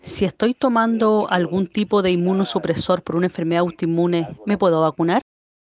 Auditores de Radio Bío Bío hicieron llegar sus consultas.